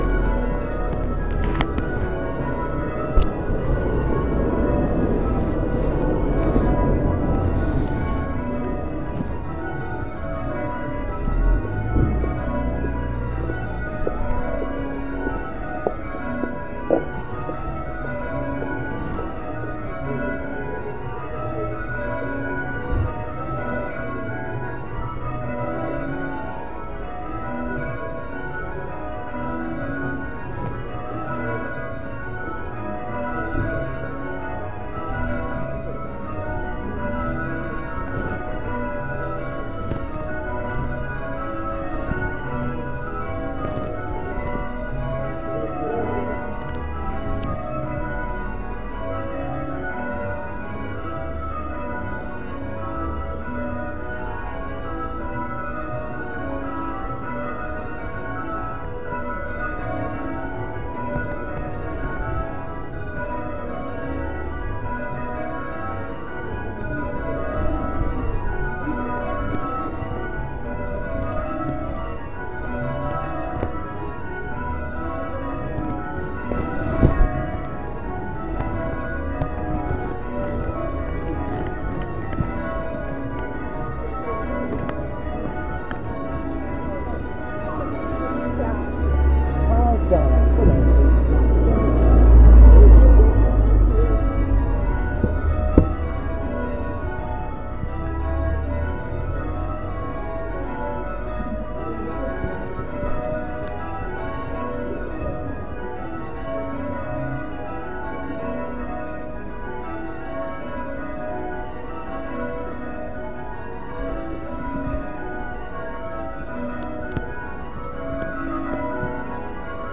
September Old St Martin's Bells 7, including someone's car radio!